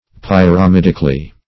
Pyr`a*mid"ic*al*ly, adv.